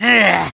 troll_archer_attack.wav